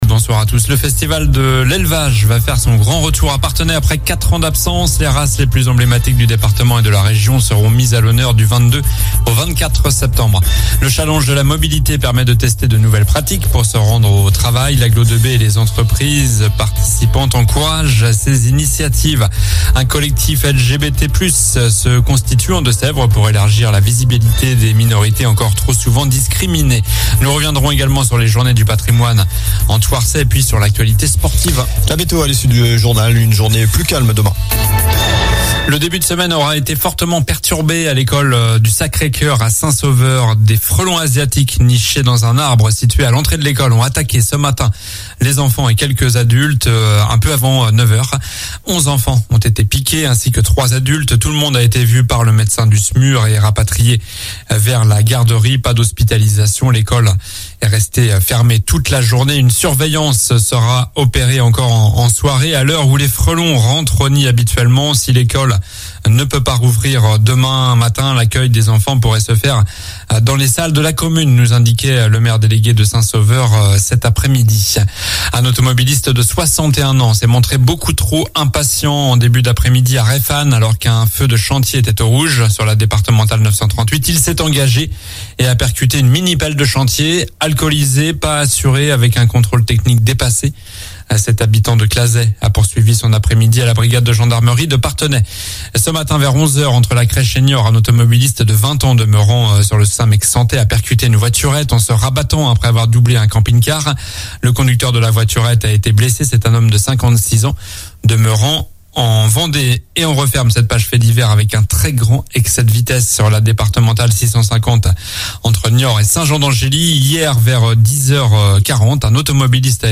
Journal du lundi 18 septembre (soir)